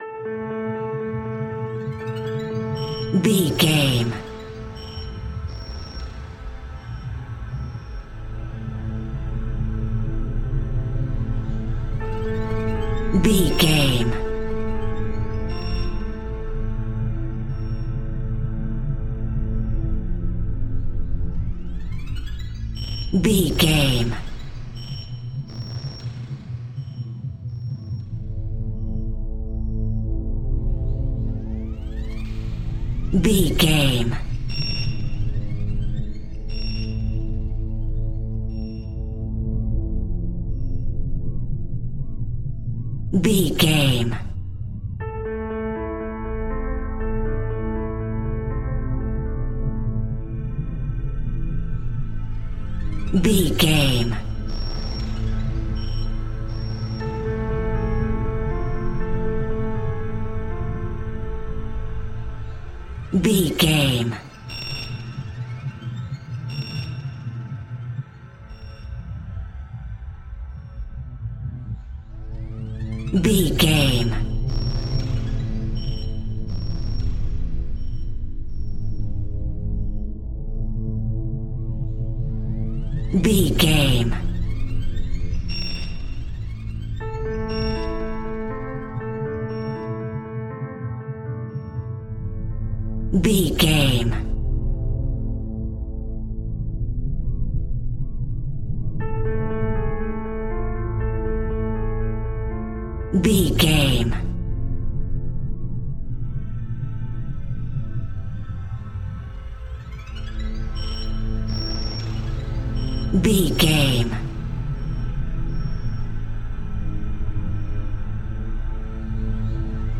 Demon Horror Music.
Aeolian/Minor
Slow
ominous
eerie
piano
synthesiser
strings
pads